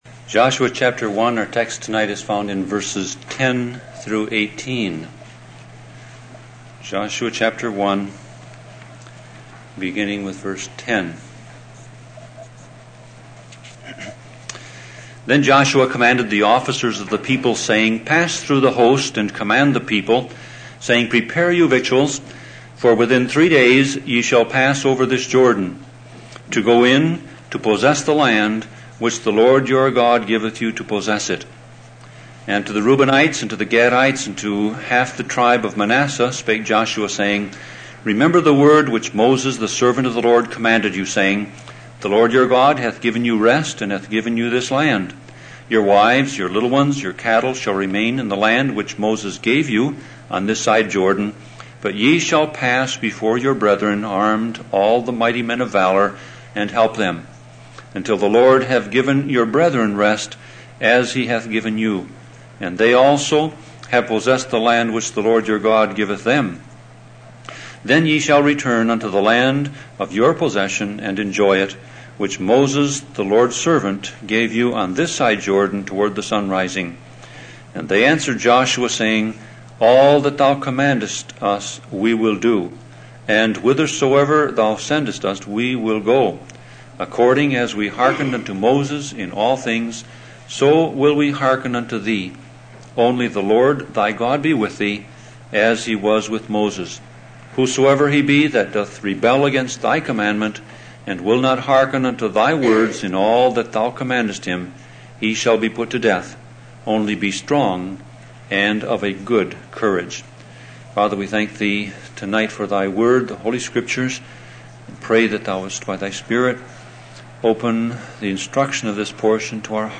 Sermon Audio Passage: Joshua 1:10-18 Service Type